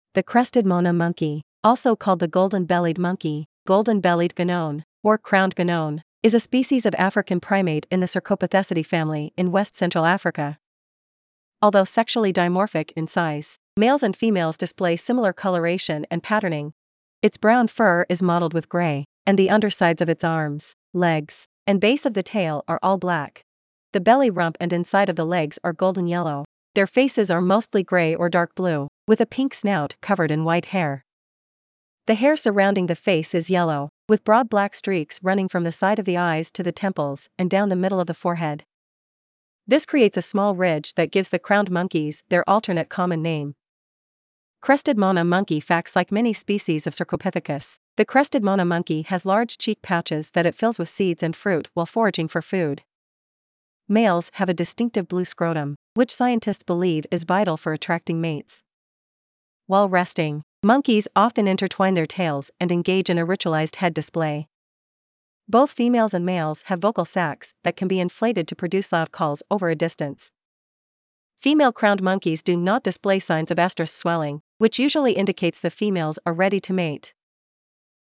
Crested Mona Monkey
• Both females and males have vocal sacs that can be inflated to produce loud calls over a distance.
Crested-Mona-Monkey.mp3